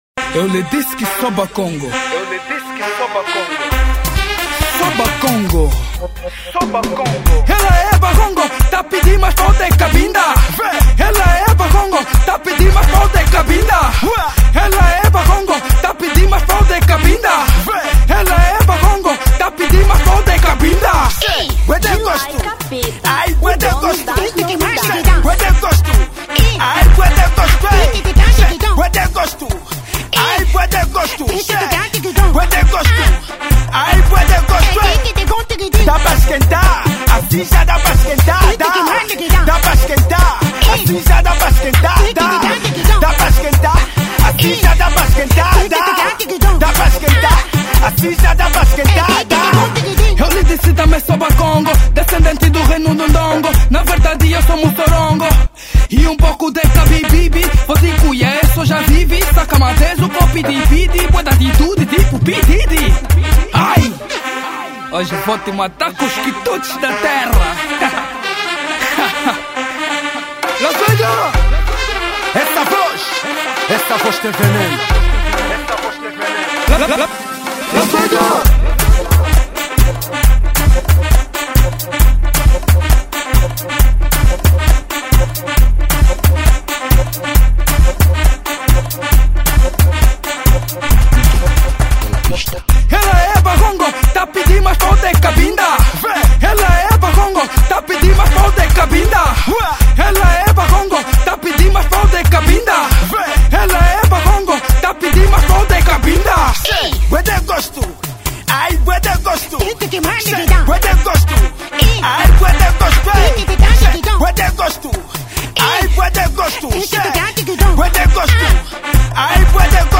Afro Edit